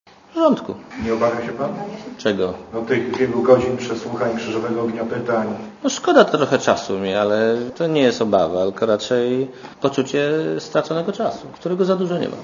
Trochę szkoda mi czasu - tak Belka skomentował wezwanie go przez komisję. Premier pytany przez dziennikarzy, czy nie obawia się przesłuchania odpowiedział: To nie jest obawa, tylko raczej poczucie straconego czasu, którego za dużo nie mam.
Mówi premier Marek Belka Trochę szkoda mi czasu - tak Belka skomentował wezwanie go przez komisję.